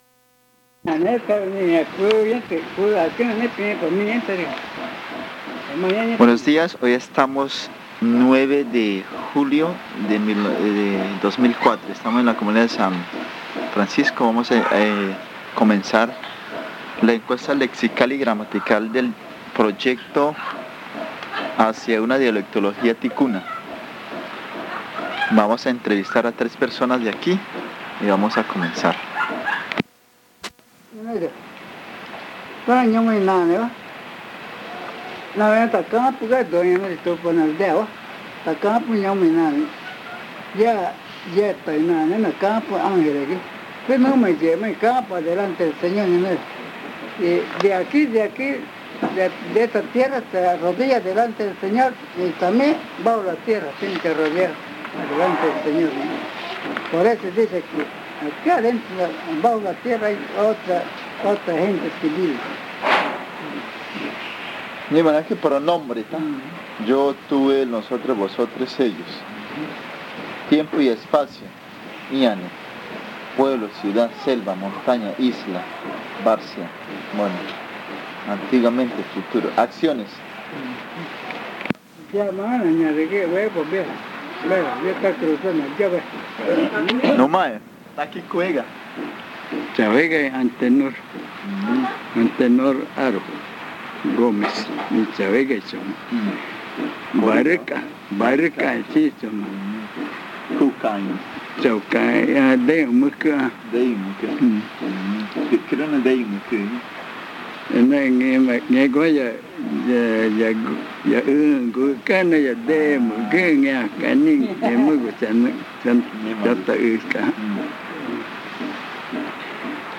El audio contiene los lados A y B del casete.